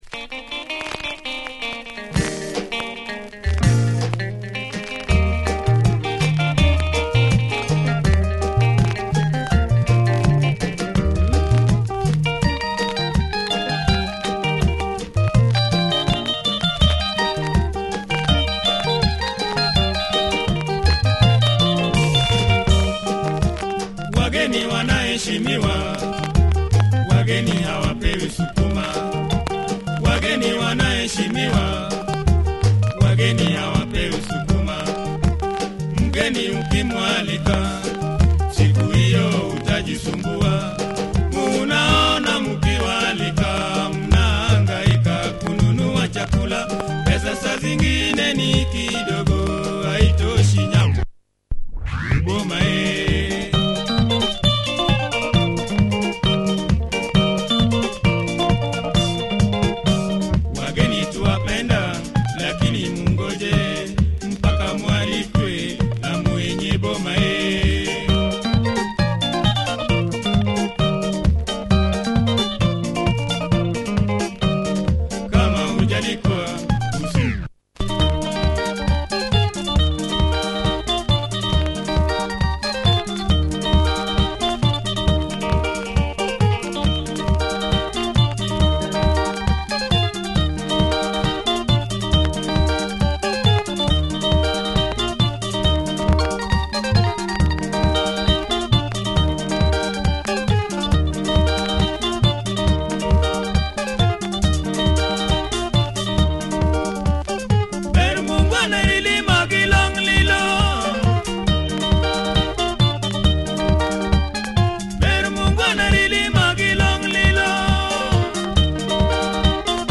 Luo Benga, sung in swahili.